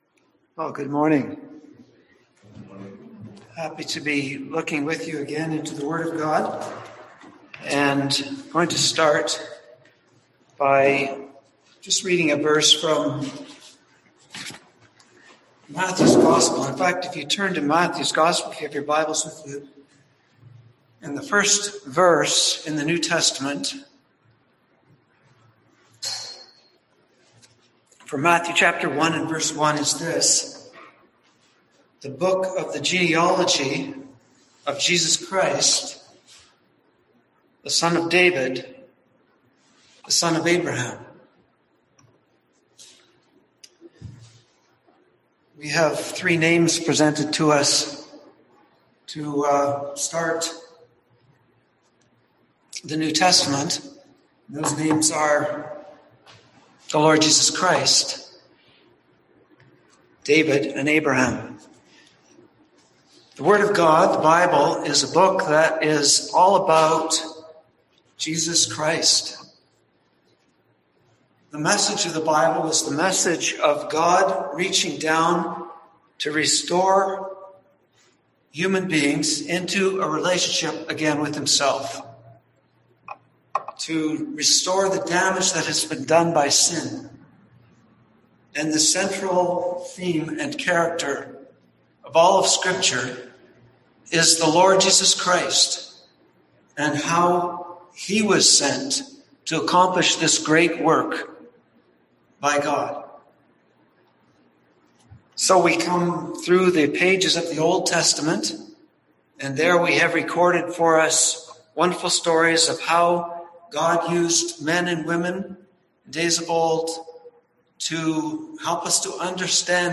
Service Type: Sunday AM Topics: Abraham